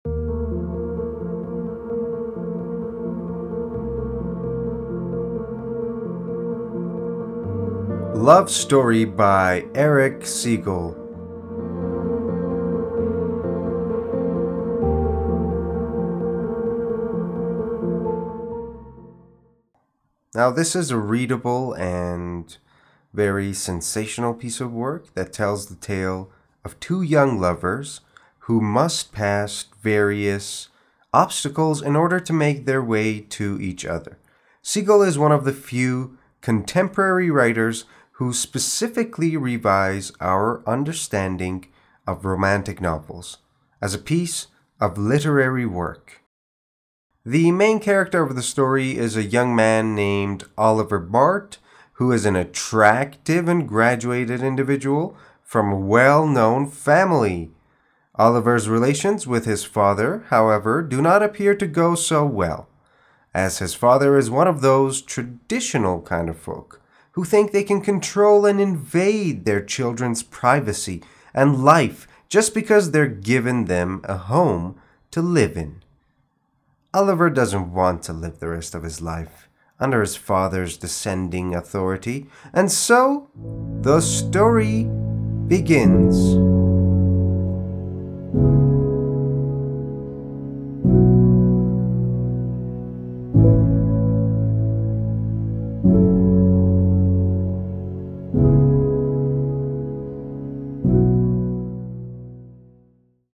معرفی صوتی کتاب Love Story